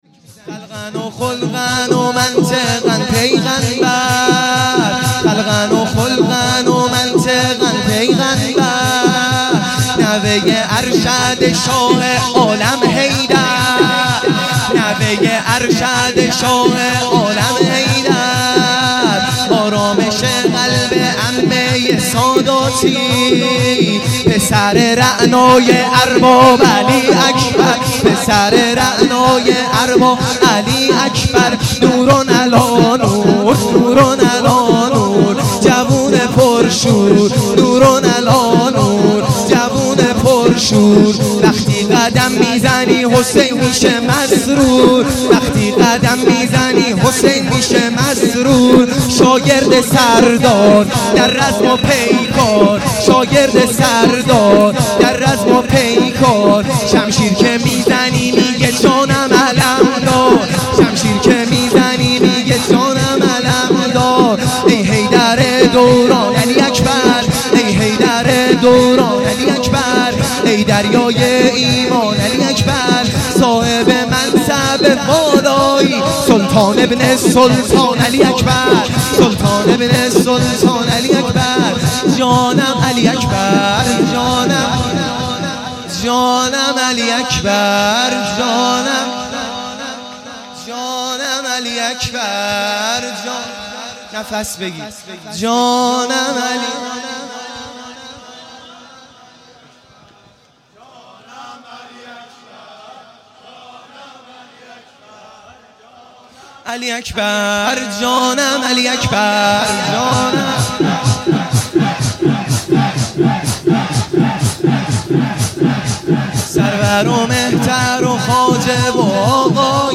شور
شب هشتم محرم الحرام ۱۳۹۶